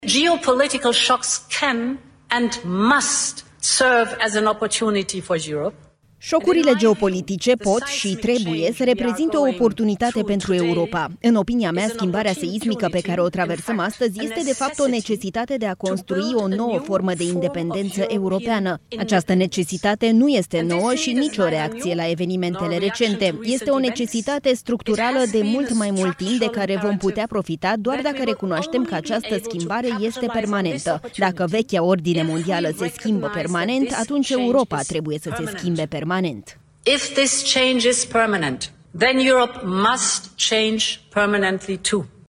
În discursul susținut la Forumul Economic Mondial de la Davos, Elveția, președinta Comisiei Europene, Ursula von der Leyen, a afirmat că Europa trebuie, de fapt, să profite de actualele frământări geopolitice pentru a obține o mai mare independență.
20ian-16-Ursula-–-Despre-independenta-Europei-TRADUS-.mp3